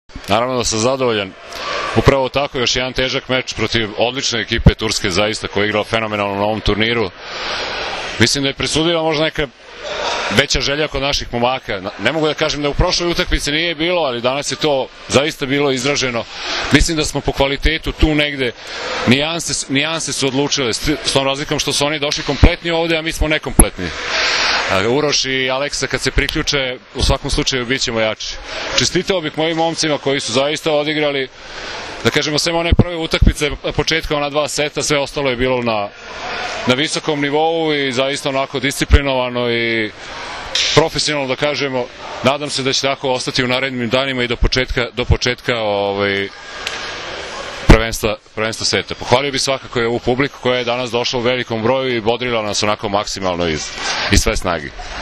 IZJAVA